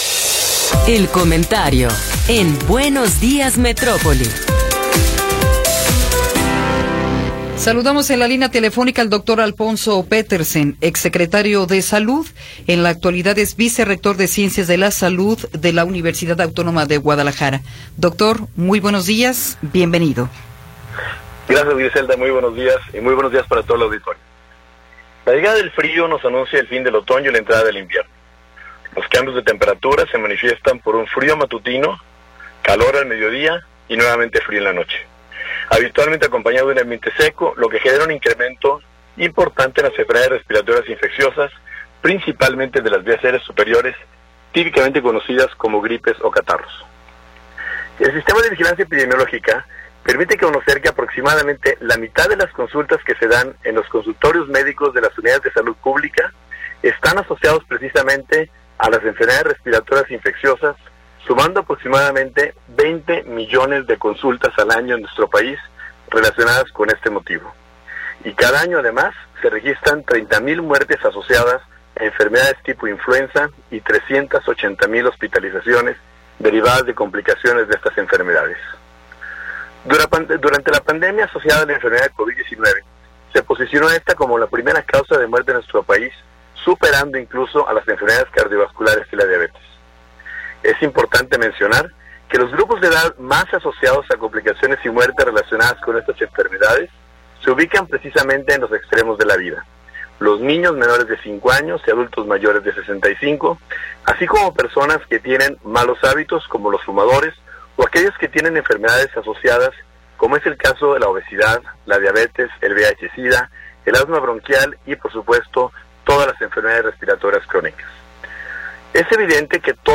Comentario de Alfonso Petersen Farah – 16 de Noviembre de 2022
El Dr. Alfonso Petersen Farah, vicerrector de ciencias de la salud de la UAG y exsecretario de salud del estado de Jalisco, nos habla sobre los cambios de temperatura y el aumento en las enfermedades respiratorias.